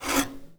I Watch It For The Plot ⁄mlp⁄/Videos/⁄mlp⁄ Dubs/⁄mlp⁄ Dubs 1 - Episode 100/Files/a sounds adventure through the intranet/Foley (HL2 Ep2)
eli_grab_frame.wav